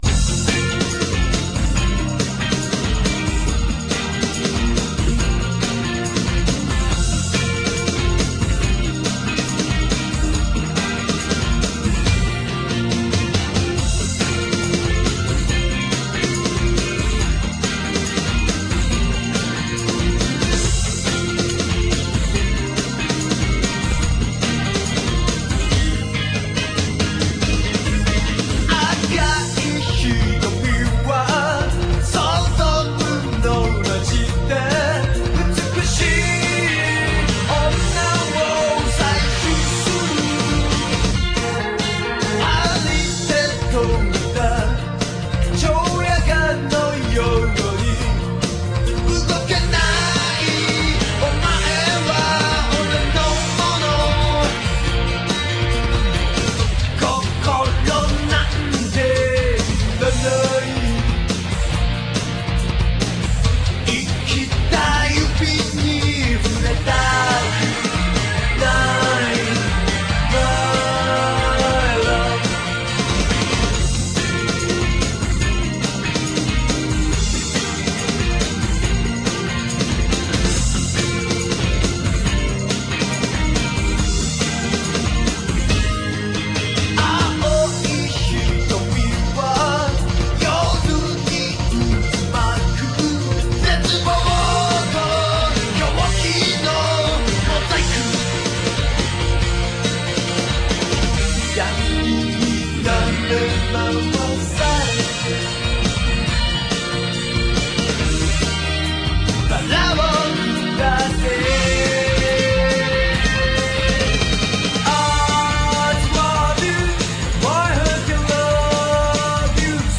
more pop than rock...but it's got a cool electronic sound.